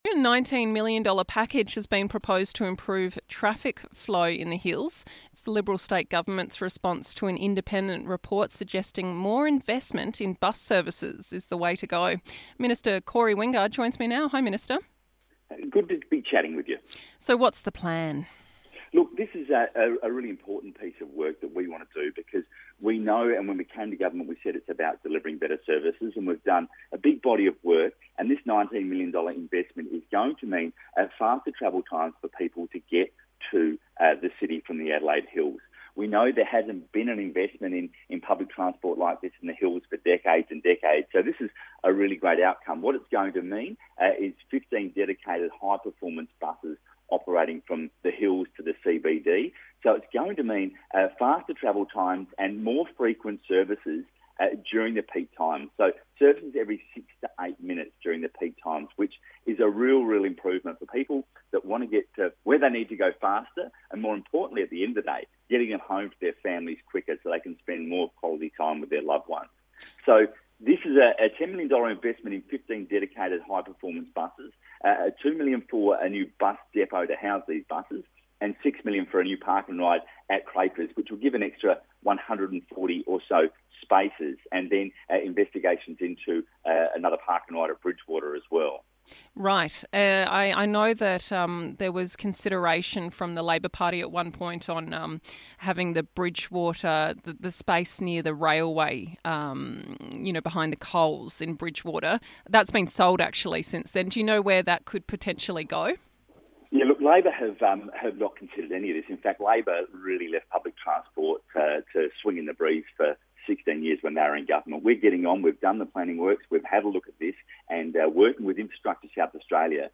Minister for Infrastructure and Transport Corey Wingard joins